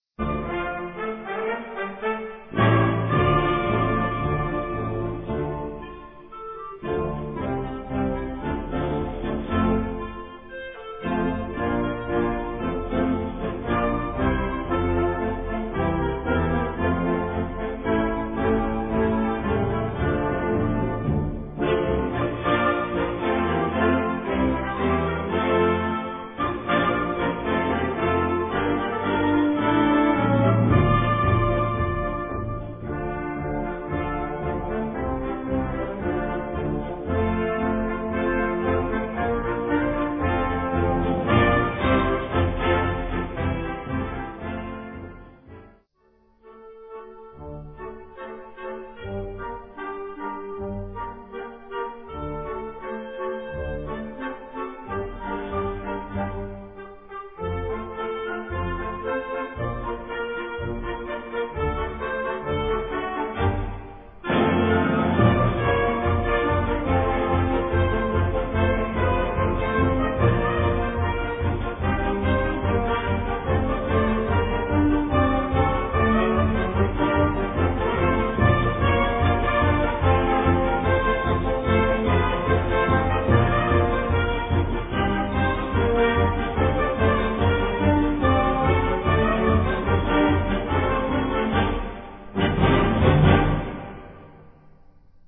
Kategorie Blasorchester/HaFaBra
Besetzung Flexi (variable Besetzung); Ha (Blasorchester)
Besetzungsart/Infos 8part; Perc (Schlaginstrument)